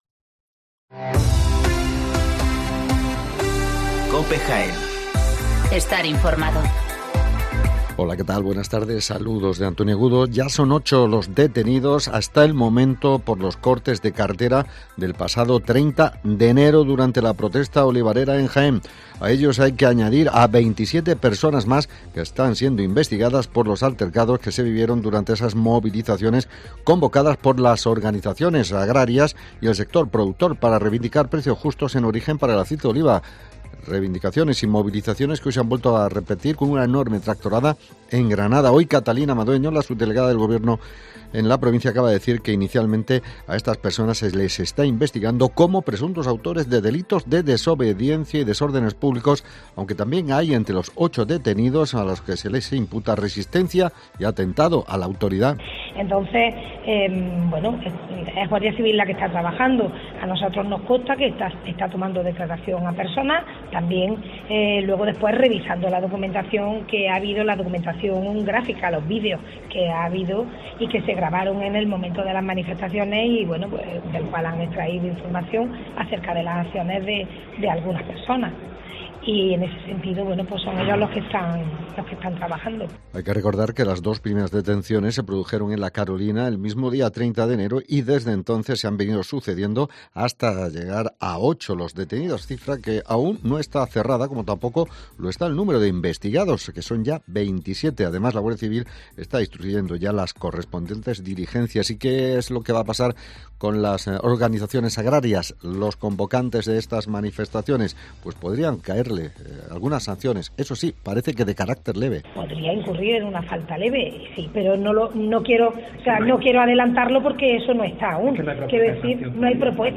Toda la actualidad, las noticias más próximas y cercanas te las acercamos con los sonidos y las voces de todos y cada uno de sus protagonistas.